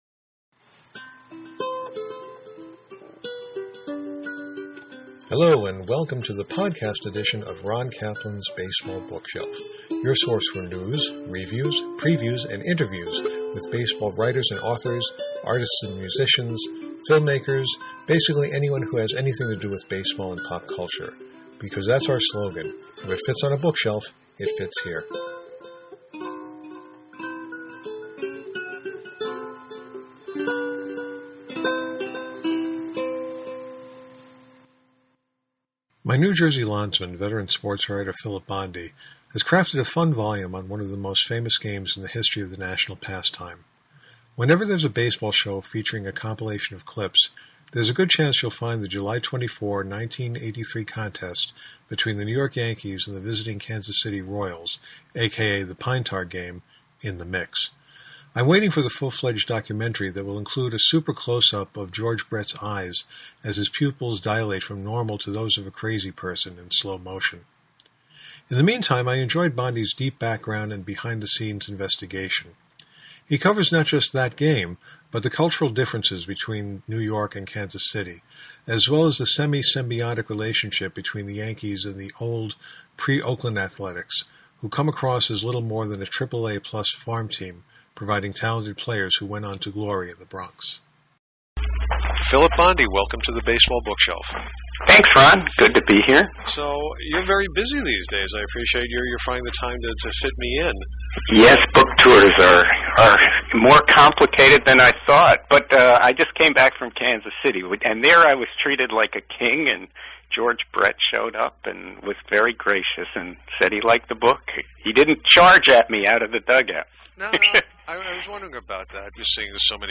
The Bookshelf Conversation